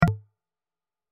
جلوه های صوتی
دانلود صدای کلیک 45 از ساعد نیوز با لینک مستقیم و کیفیت بالا
برچسب: دانلود آهنگ های افکت صوتی اشیاء دانلود آلبوم صدای کلیک از افکت صوتی اشیاء